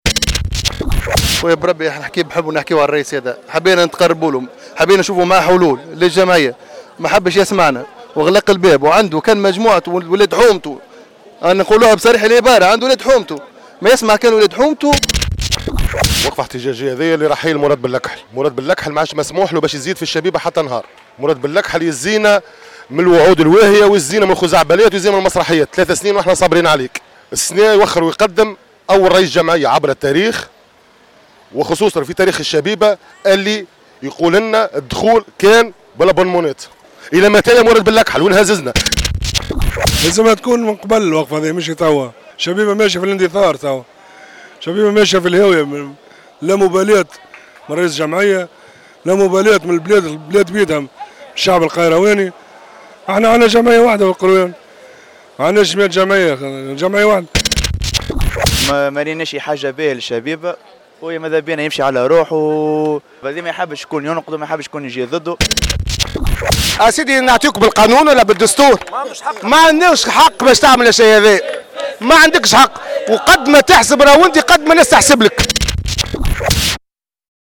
تصريحات البعض من احباء الشبيبة :